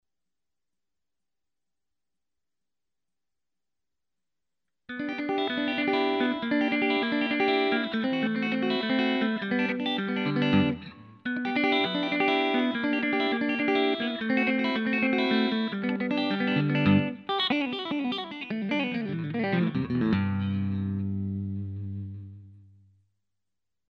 TeleBites...HEAR a Tele in action!